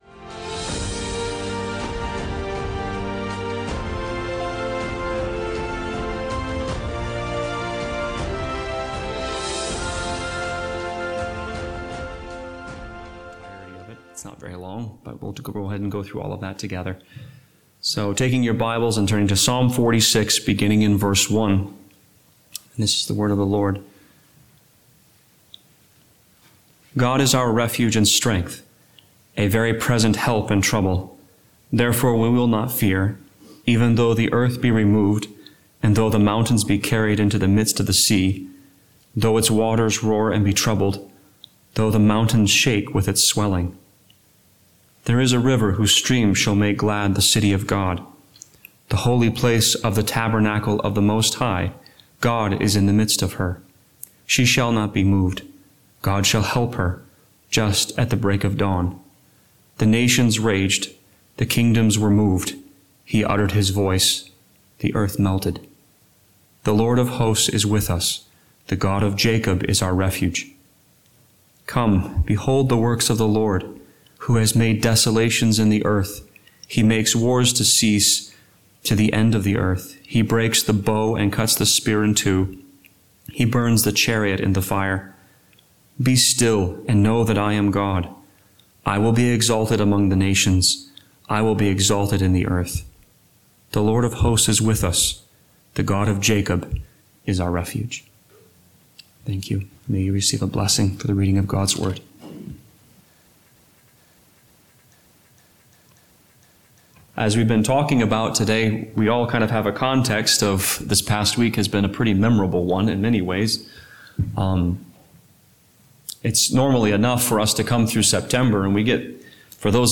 Psalm 46 Service Type: Sunday Morning Worship When the world shakes